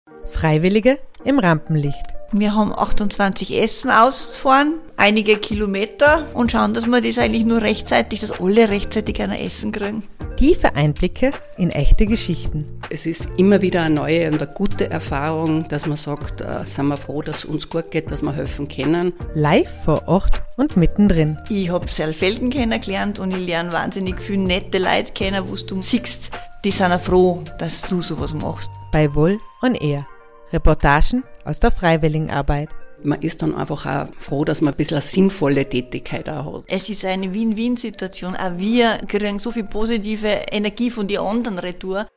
Trailer #9
direkt vor Ort, mit viel Interaktion und spannenden Gesprächen.